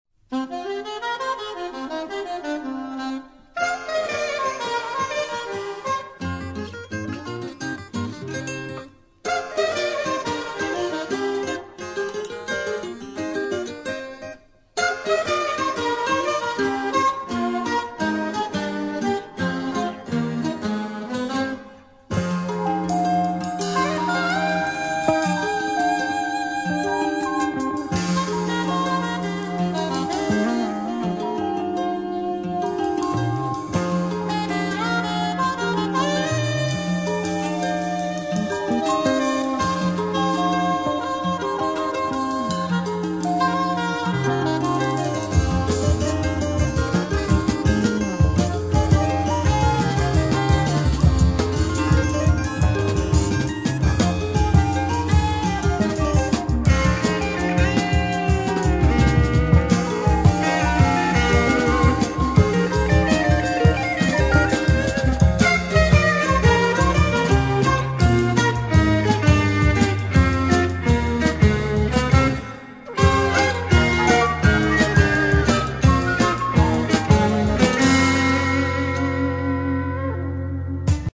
Saxophones soprano, alto et tenor, flute, voix
Guitares, voix
Claviers, voix, arrangements
Batterie, voix
Enregistré au Studio Miraval - 1980